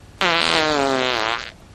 an awesome fart